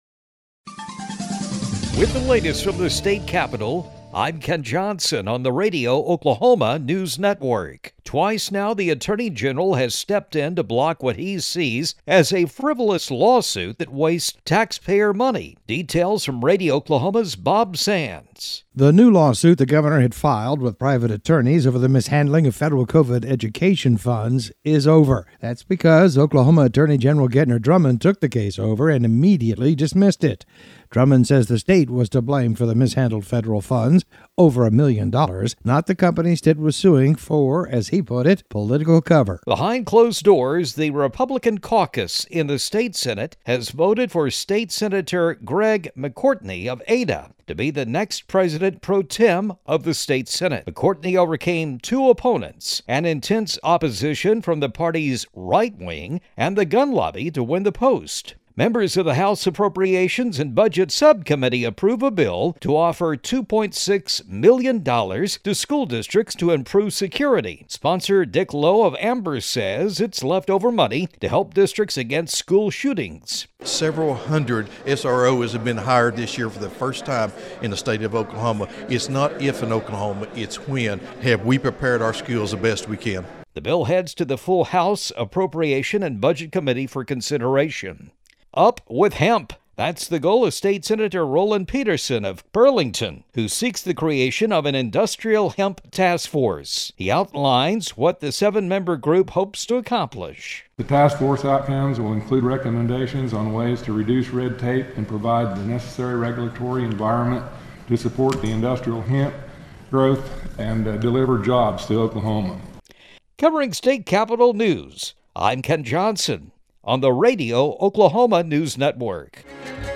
The latest news from the Oklahoma State Capitol is available daily on the Radio Oklahoma News Network.